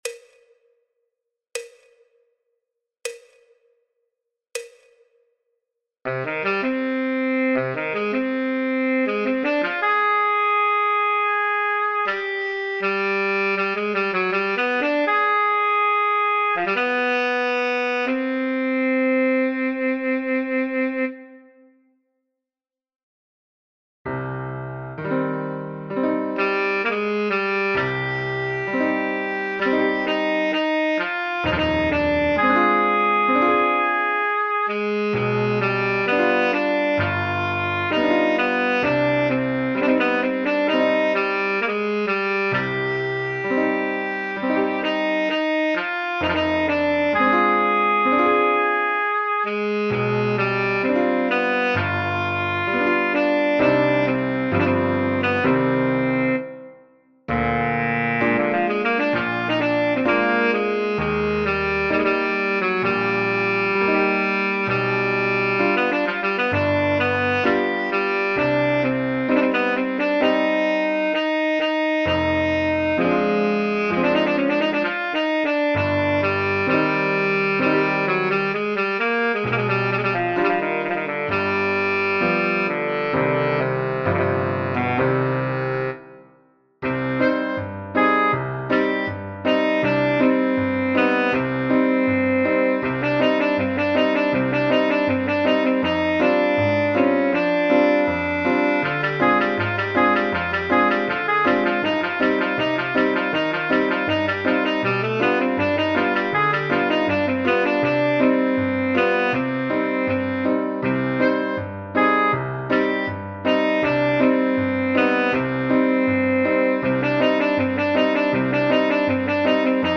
TONALIDAD EN RE MENOR (D minor Easy) FÁCIL.
Incluye PISTA KARAOKE en mp3.